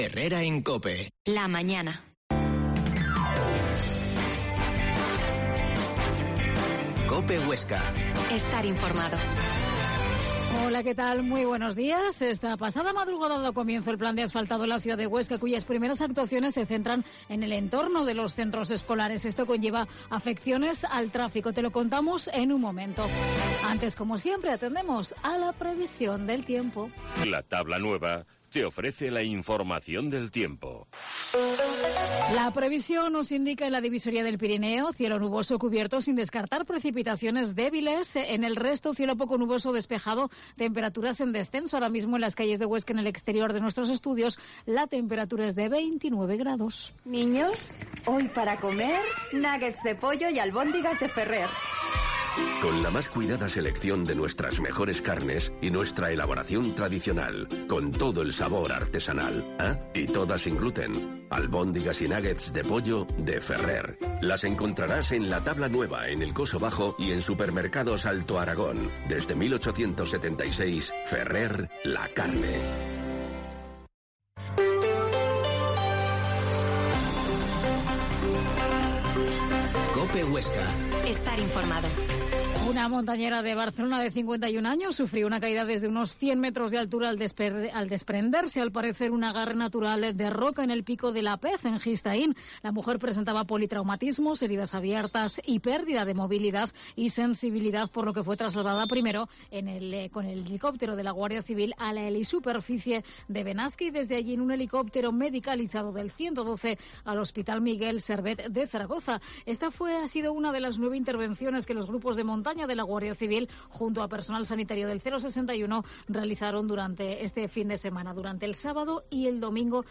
Herrera en COPE Huesca 12.50h Entrevista a Álvaro Salesa, Alcalde de Castiello de Jaca